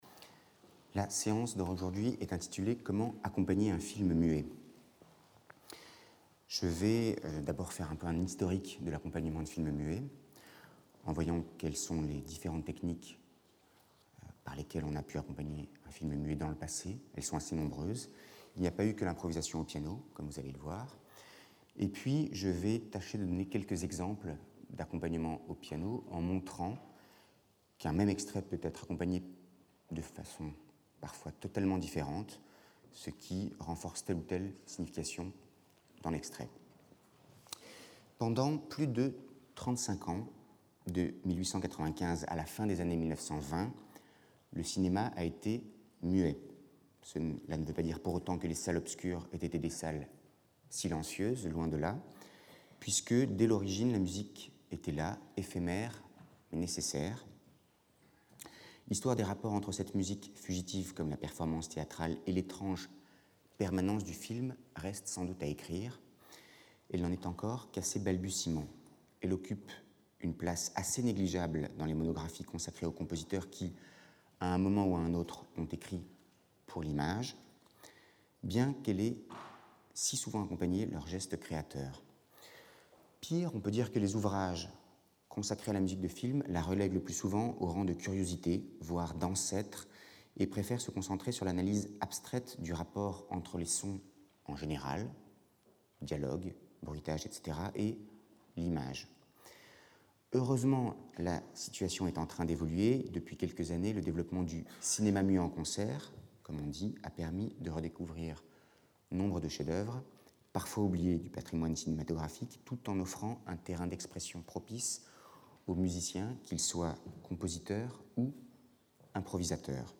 Beffa : improvisations ; 6 min.
Trio pour deux violoncelles et piano